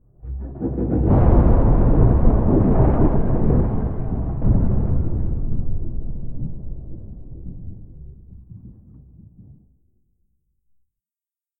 Commotion6.ogg